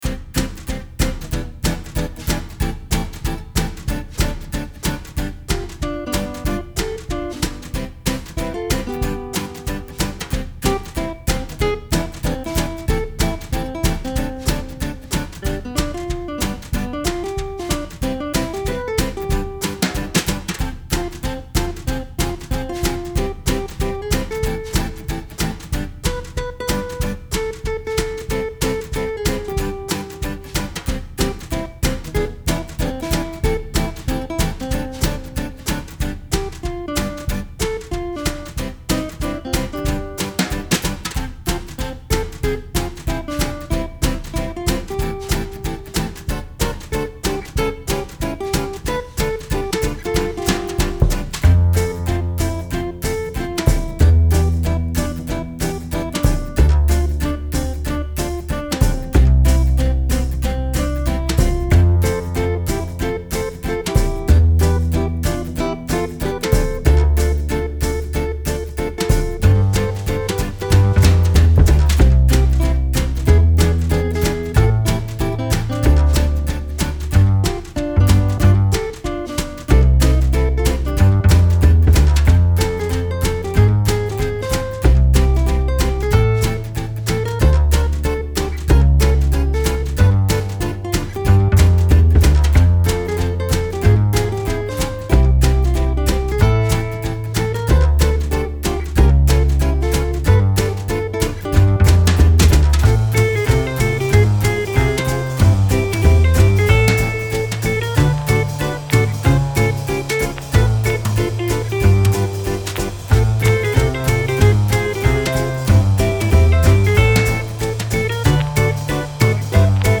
Style Style Jazz, World
Mood Mood Cool, Relaxed
Featured Featured Acoustic Guitar, Bass, Drums
BPM BPM 93.39